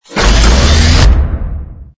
ui_equip_mount02.wav